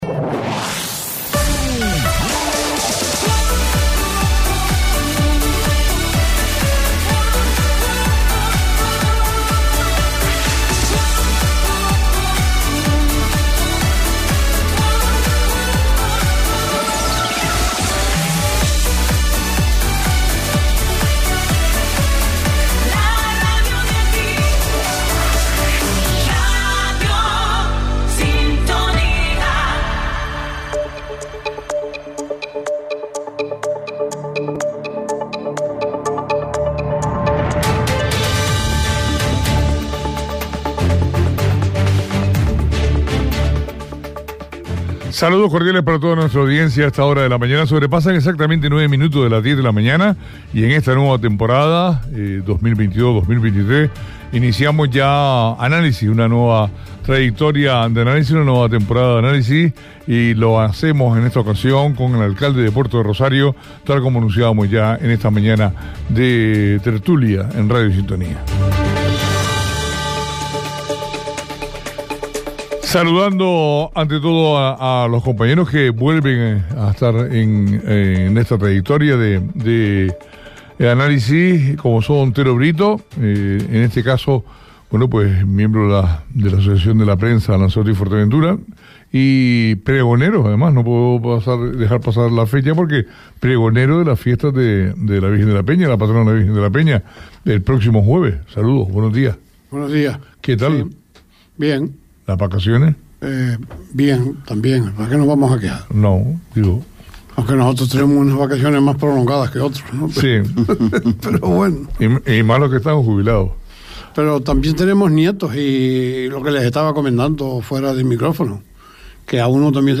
En nuestro primer Análisis de esta temporada, hemos contado con la visita del alcalde de Puerto Del Rosario, Juan Jiménez.
Entrevistas En nuestro primer Análisis de esta temporada, hemos contado con la visita del alcalde de Puerto Del Rosario, Juan Jiménez.